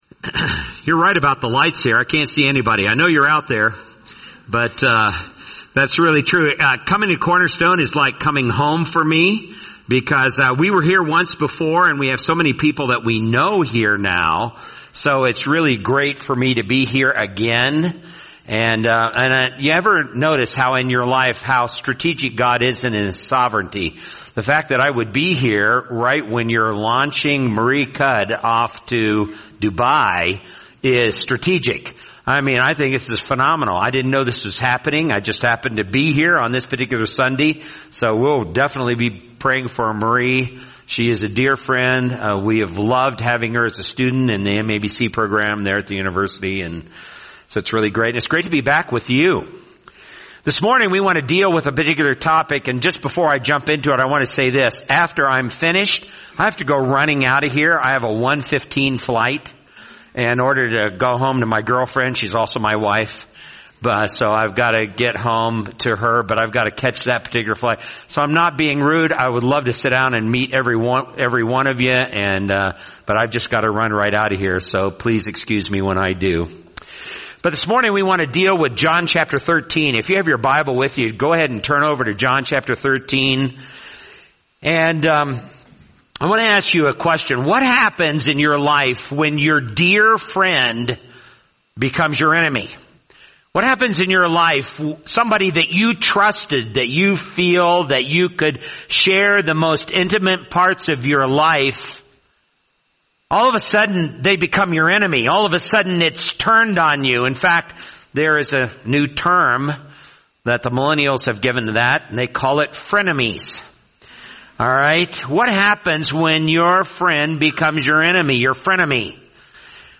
[sermon]